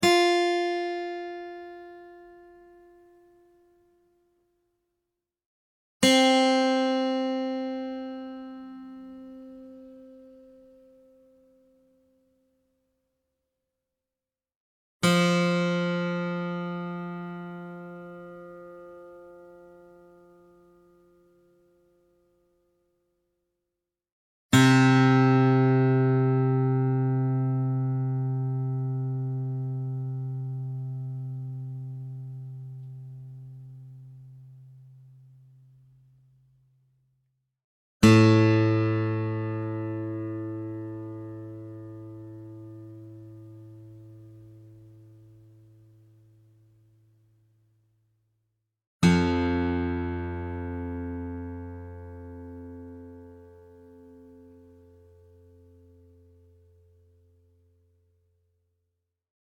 Real acoustic guitar sounds in Open F Tuning
Guitar Tuning Sounds